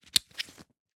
pickup_sound.mp3